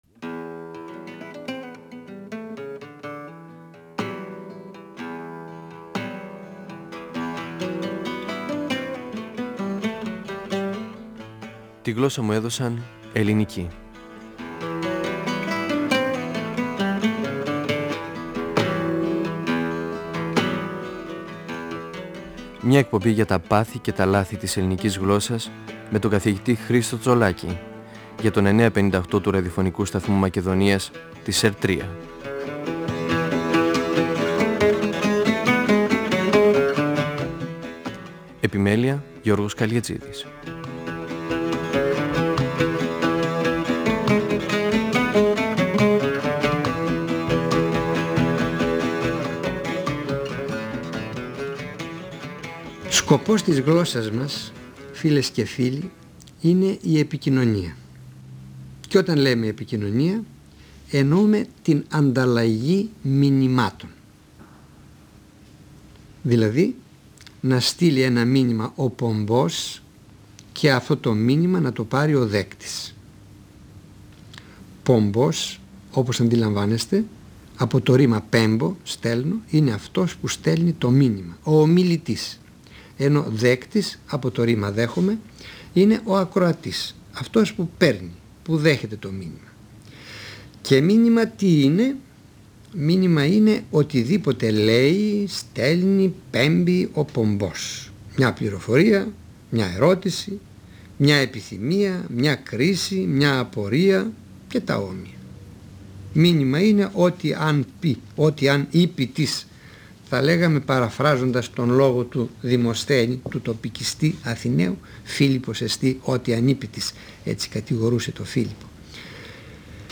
Ο γλωσσολόγος Χρίστος Τσολάκης (1935-2012) μιλά για τον σκοπό της γλώσσας και για τις επιστήμες που ασχολούνται με την επικοινωνία: επικοινωνιολογία, πληροφορητική, κυβερνητική. Αναφέρεται στον πομπό, τον δέκτη, τον κώδικα, το μήνυμα, τον δίαυλο, τις συνθήκες, τις λειτουργίες εγγραφής, τις λειτουργίες αποκωδικοποίησης, τις φάσεις του μηνύματος.